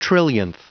Prononciation du mot trillionth en anglais (fichier audio)
Prononciation du mot : trillionth